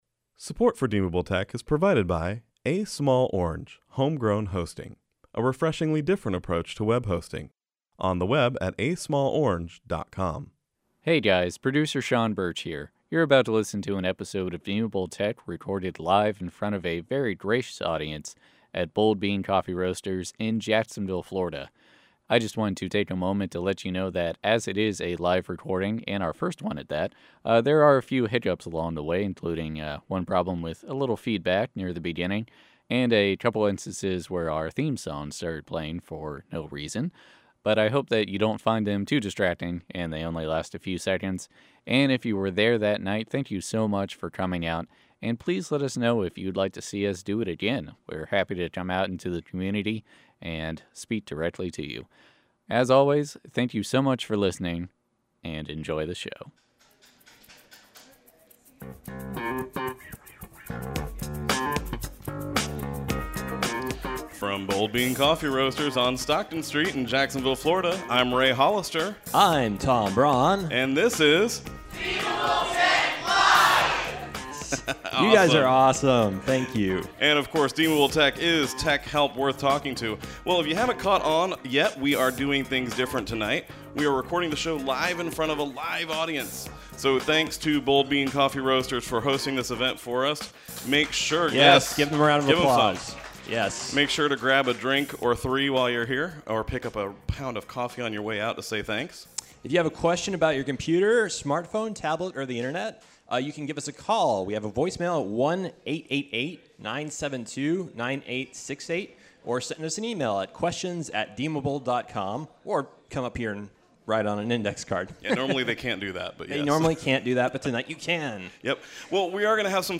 Episode 31 - Deemable Tech Live at Bold Bean Coffee Roasters - Deemable Tech
But, it wasn’t long and they had the crowd warmed up, and everybody had a great time.